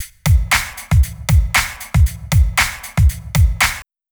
TSNRG2 Breakbeat 005.wav